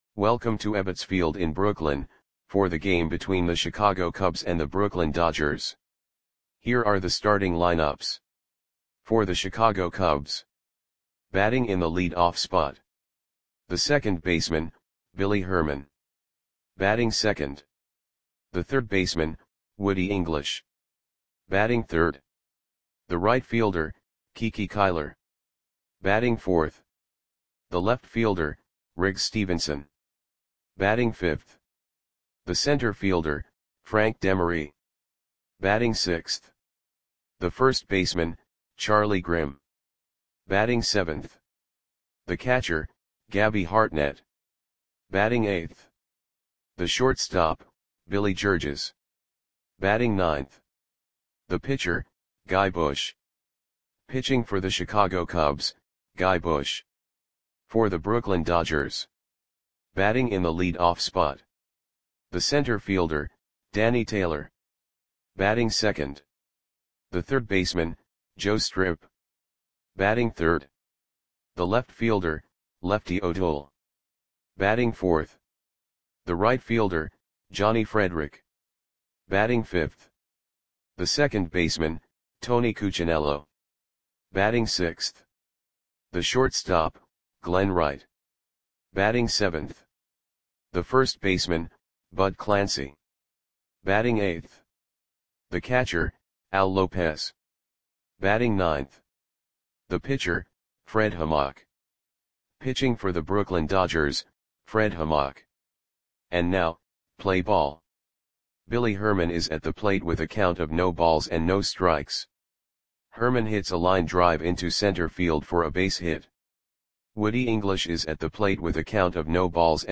Audio Play-by-Play for Brooklyn Dodgers on September 11, 1932
Click the button below to listen to the audio play-by-play.